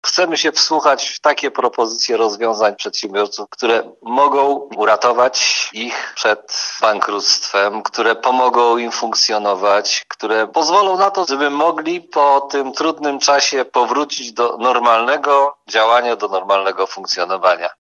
Mówi prezydent Tarnobrzega, Dariusz Bożek.